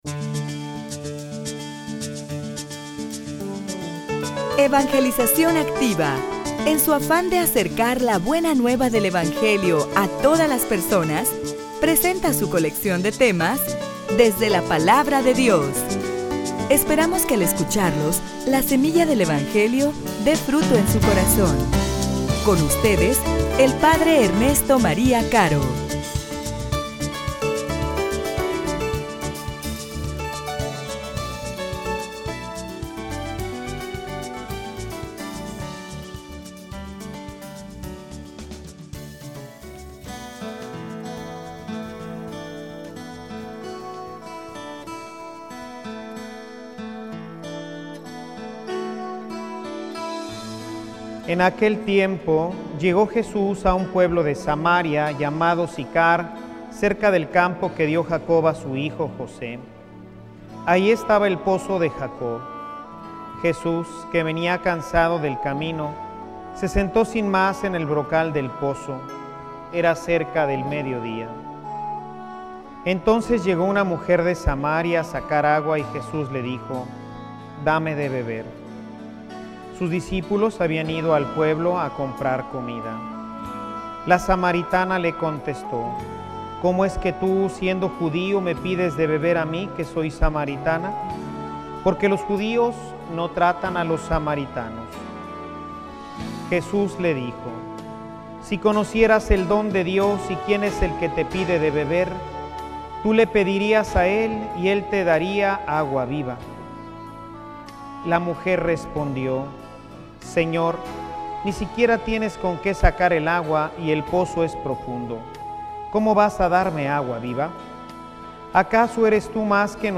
homilia_Y_tu_de_que_te_alimentas.mp3